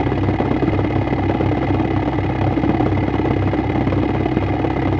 spaceEngineSmall_001.ogg